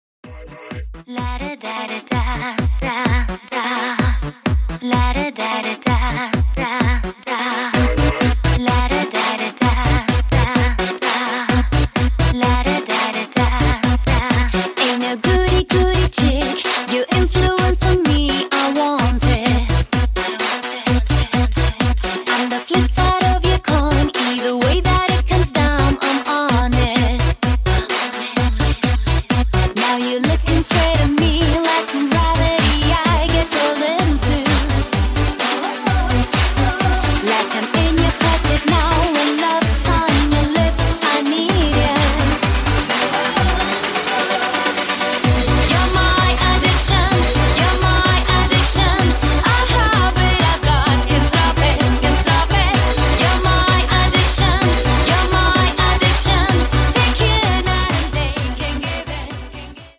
club music , dance music , edm , pop music